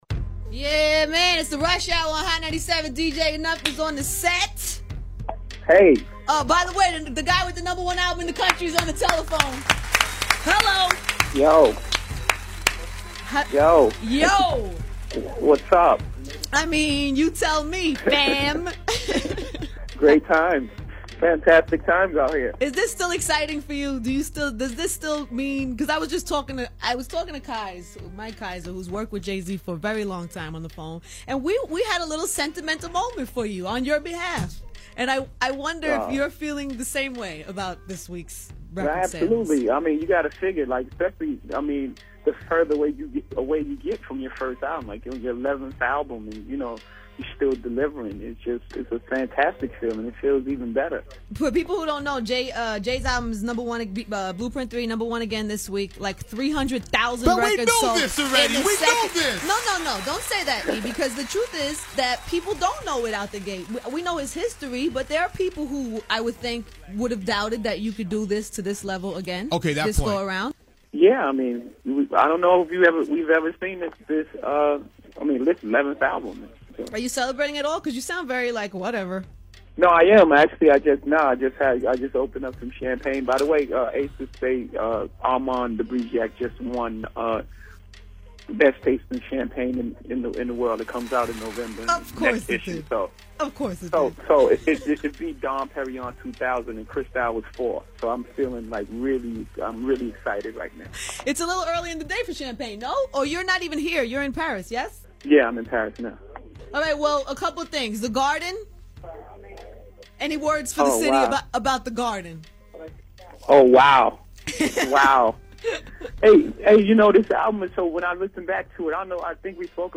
Jay-Z Calls Angie Martinez Hot97 Sep 23
Interviews
Jay-Z-Calls-Angie-Martinez-Hot97-Sep-23.mp3